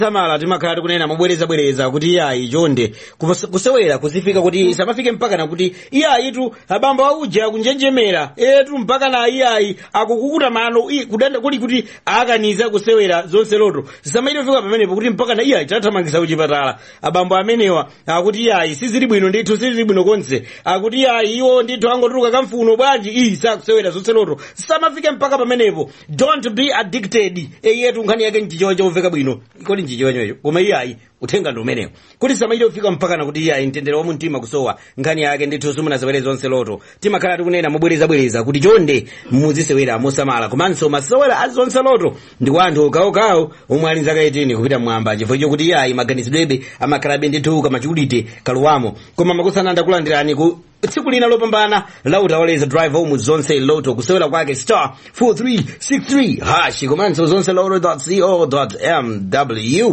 Advert: Be Mindful of Betting Addictions